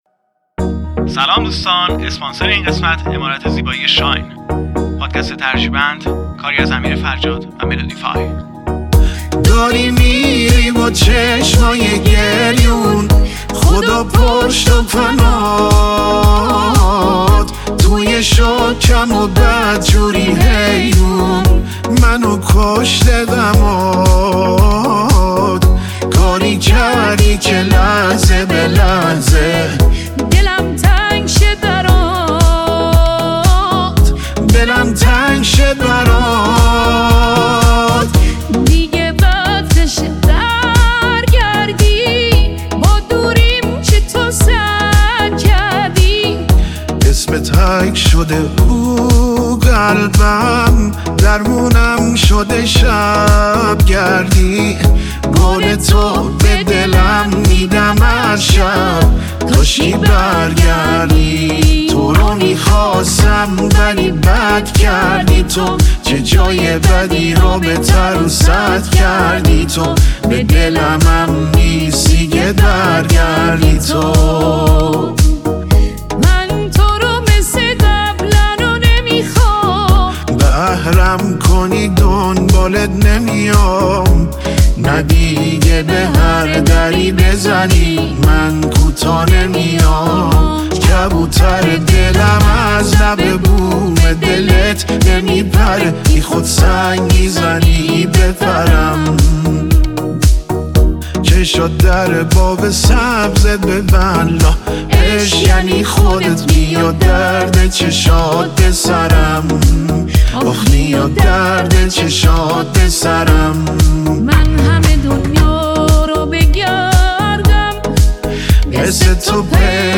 ورژن هوش مصنوعی